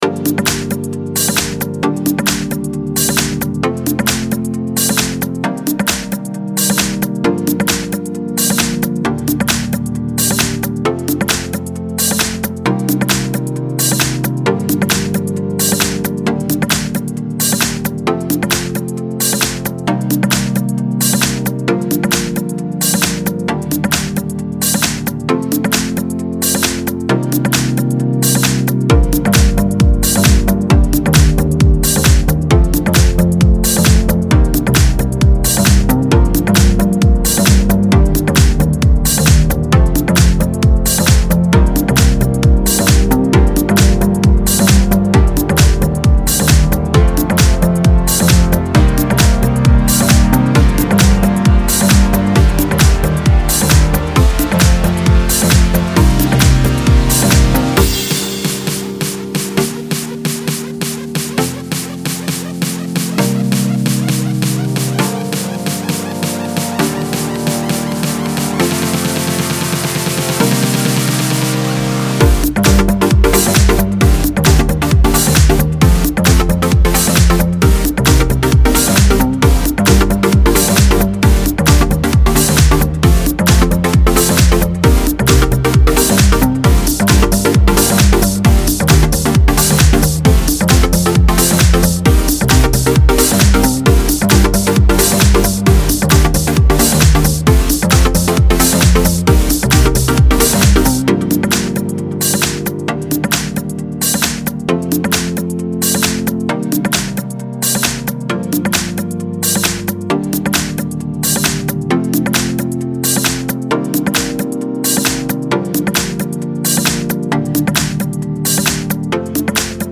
描述：舞蹈和电子音乐|欢快
Tag: 合成器 贝司